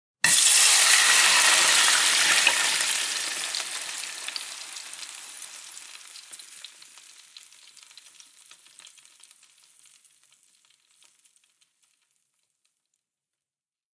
SFX在热水锅上倒水(Pour Water On Hot Pan)音效下载